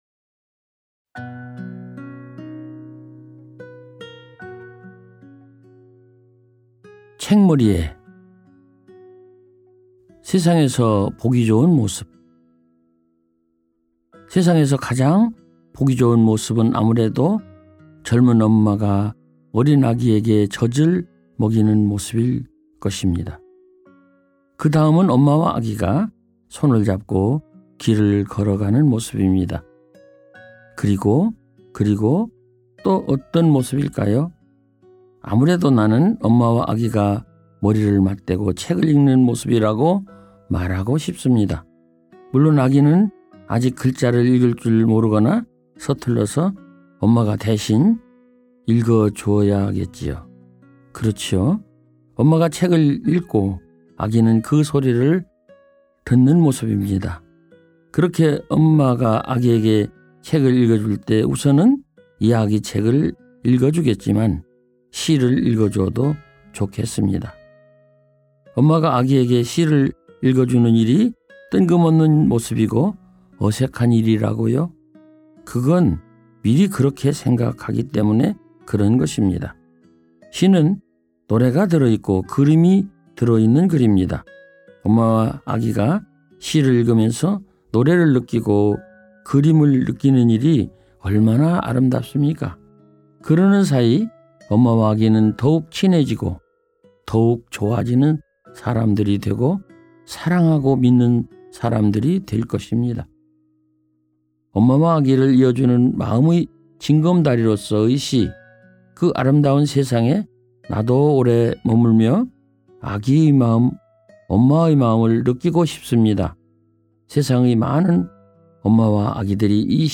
나태주 시인이 읽어 주는 편지, 그리고